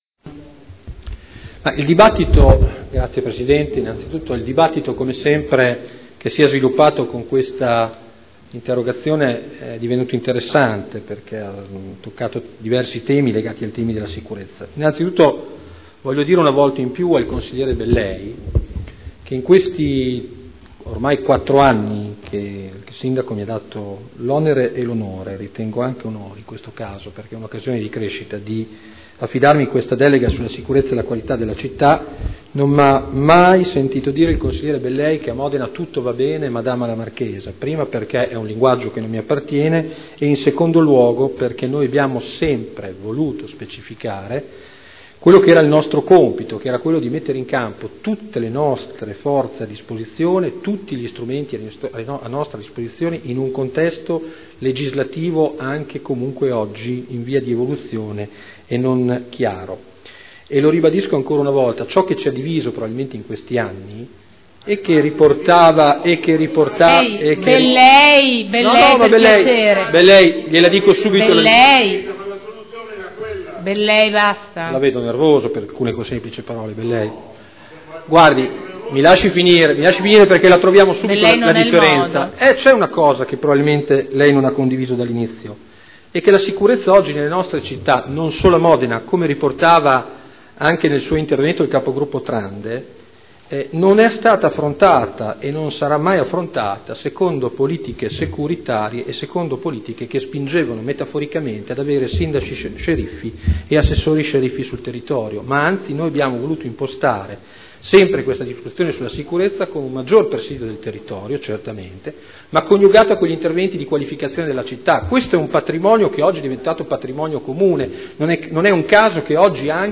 Antonino Marino — Sito Audio Consiglio Comunale